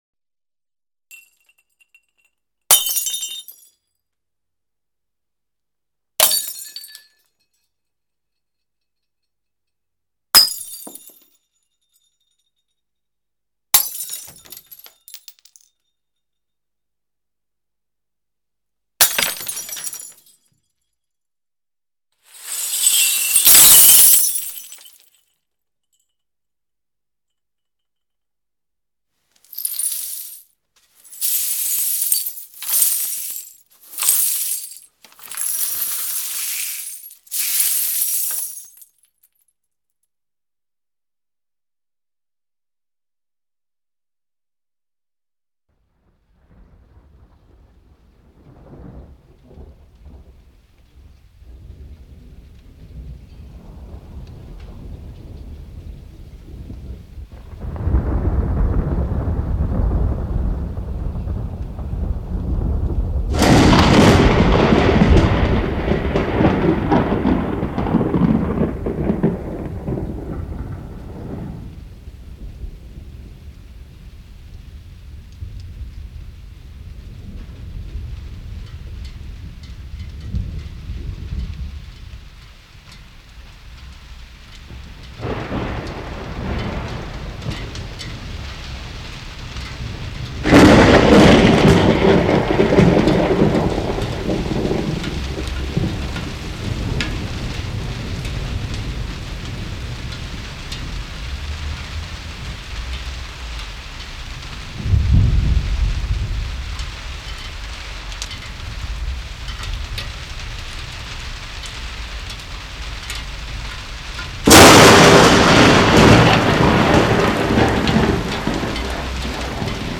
[分享]摔玻璃声，清扫玻璃声。(音效 VBR(EAC + LAME))